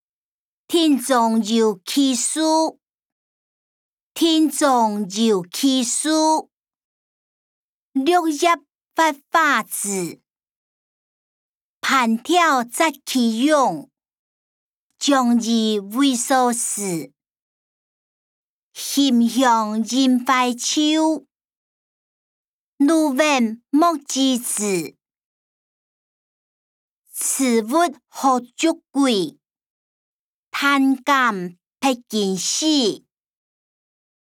古典詩-庭中有奇樹音檔(饒平腔)